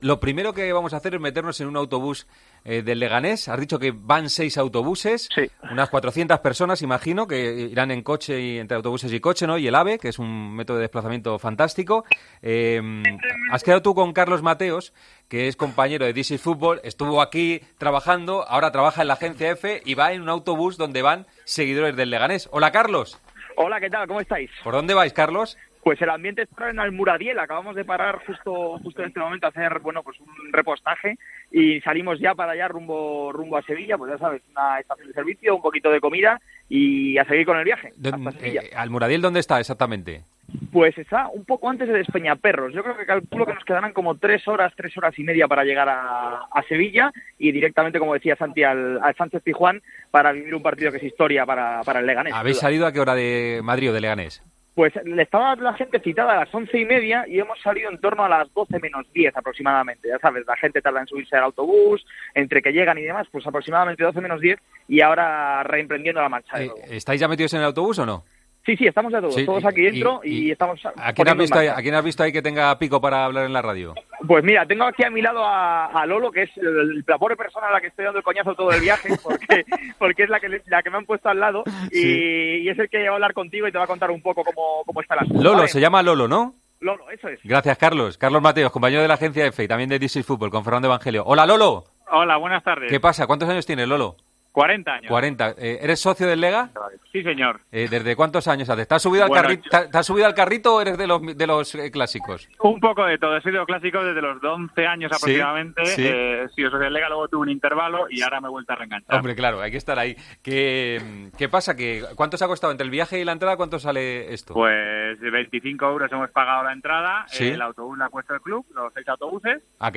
El Leganés estará acompañado por casi medio millar de aficionados este miércoles en el Pizjuán. En Deportes COPE hablamos con uno de esos hinchas pepineros que estará animando a su equipo en Sevilla.